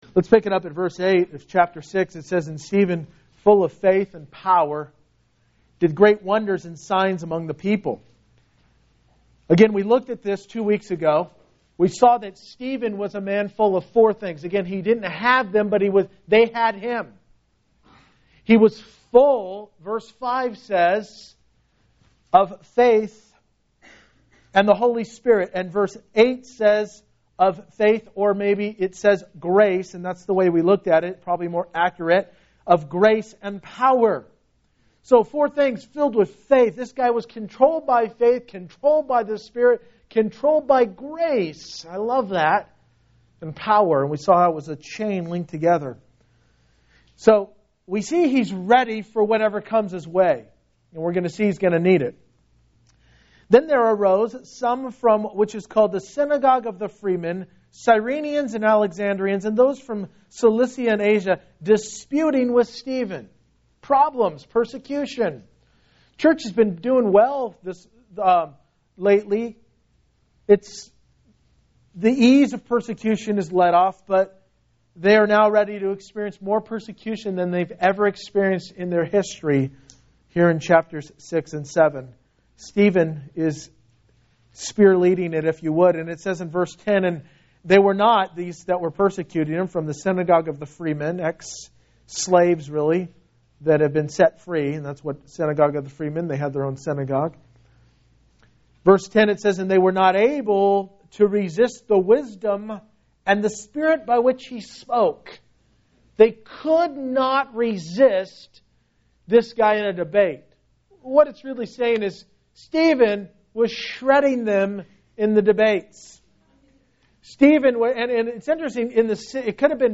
The Only Sermon of Stephen